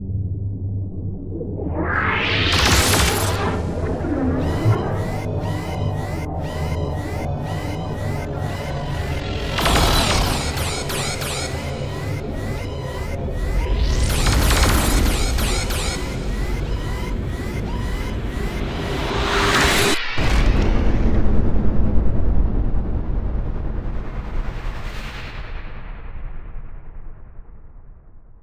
Space Battle